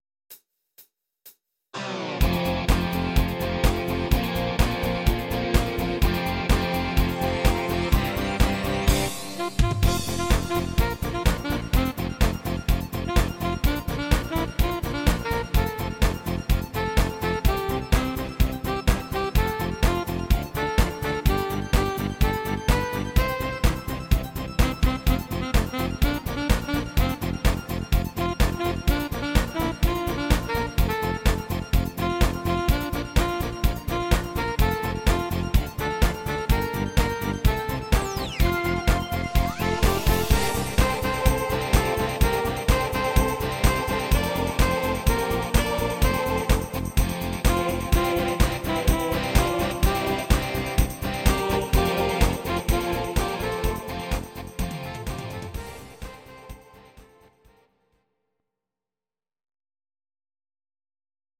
Audio Recordings based on Midi-files
German, 2000s